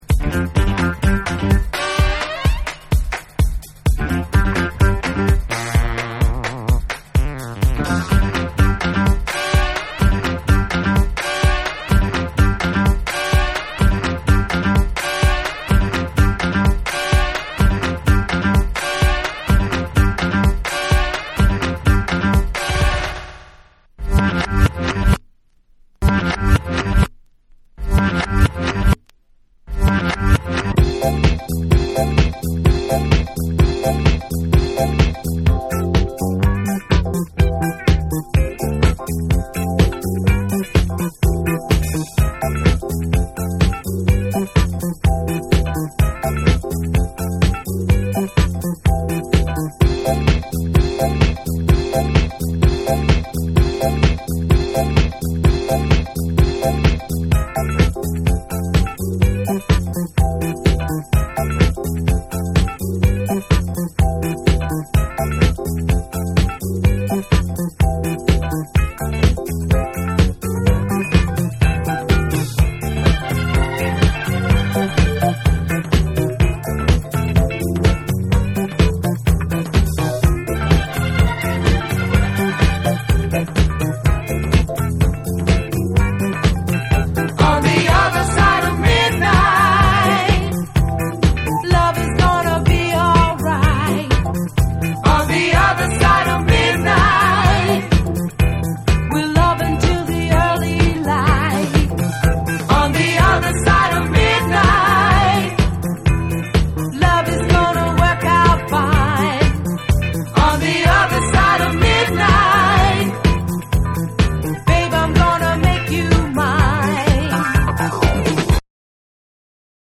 アップリフトなディスコ・ナンバーを超絶カット・アップでフロア・ユースに仕立てた
TECHNO & HOUSE / RE-EDIT / MASH UP